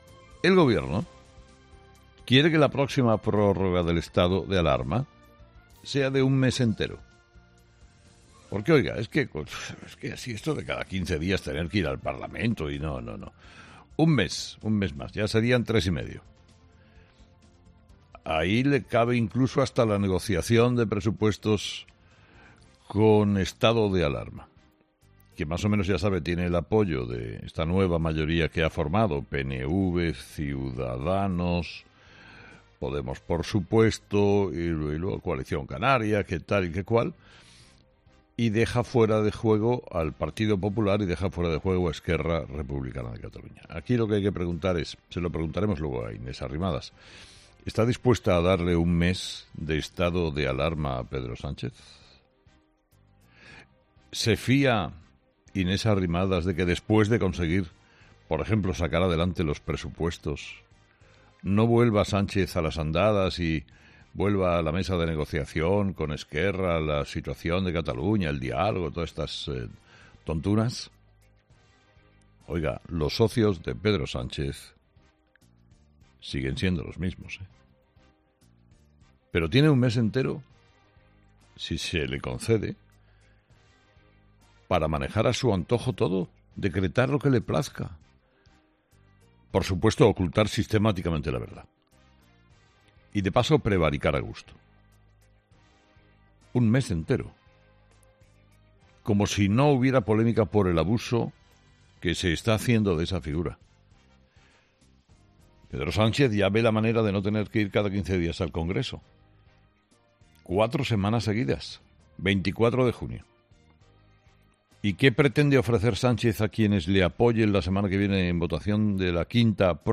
Carlos Herrera, director y presentador de 'Herrera en COPE' ha arrancado el programa de este miércoles analizando la intención de Pedro Sánchez de prolongar el estado de alarma durante un mes, acabando con las sucesivas prórrogas que se han venido produciendo hasta ahora desde que el coronavirus provocara una auténtica crisis sanitaria y social en nuestro país.